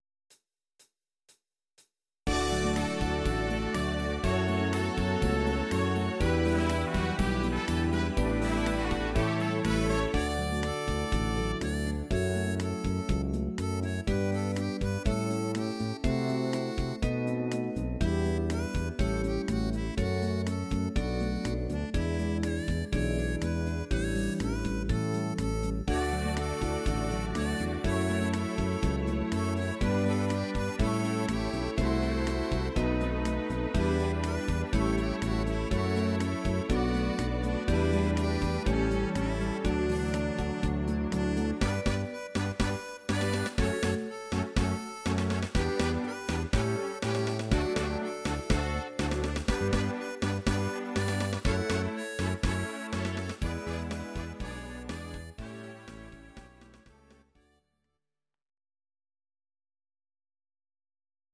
Audio Recordings based on Midi-files
Pop, Oldies, 1950s